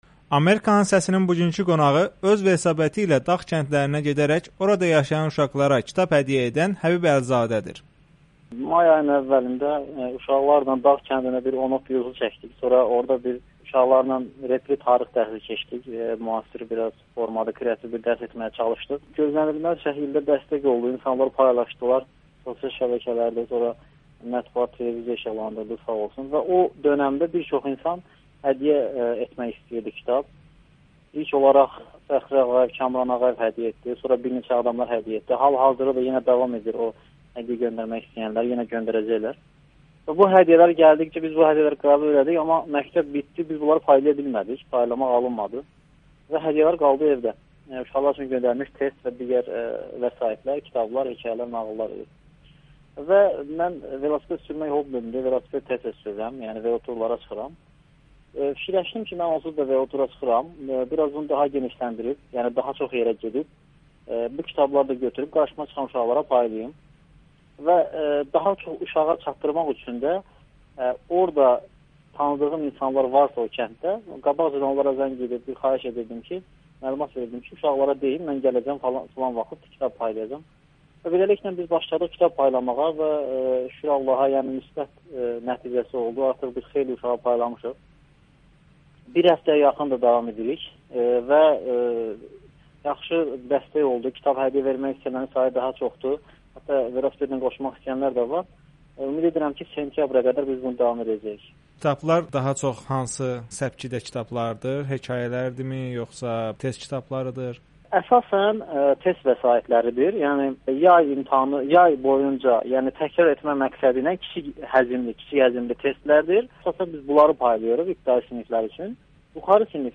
Kəndlərdə uşaqlara kitab paylayan tarix müəllimi [Audio-Müsahibə]